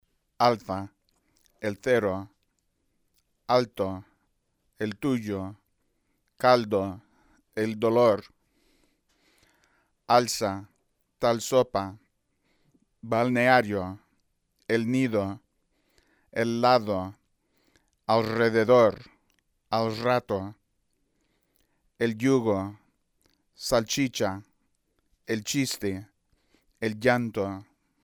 La distribución complementaria del fonema /l/ del español (el Cuadro 16.2).